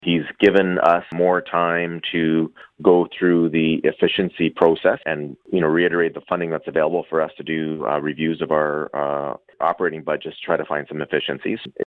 Speaking to Quinte News from the AMO conference in Ottawa, Belleville Mayor Mitch Panciuk said there is one good sign.